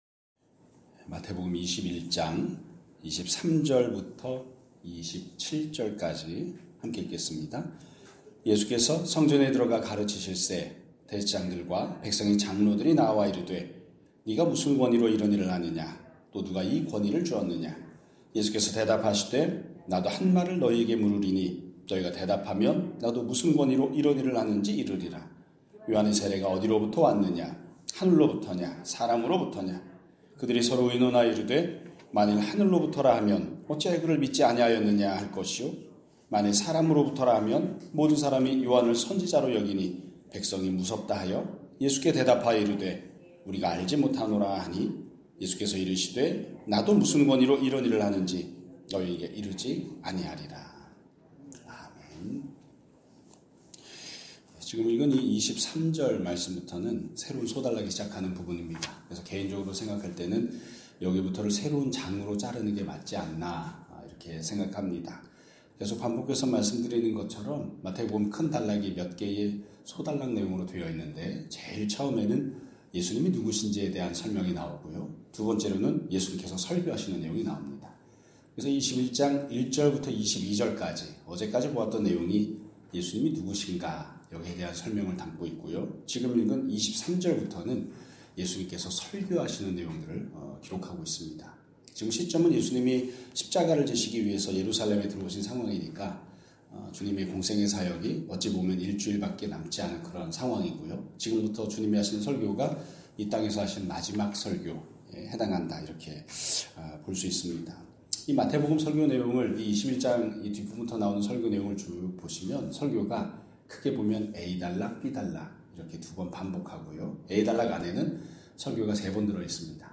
2026년 1월 30일 (금요일) <아침예배> 설교입니다.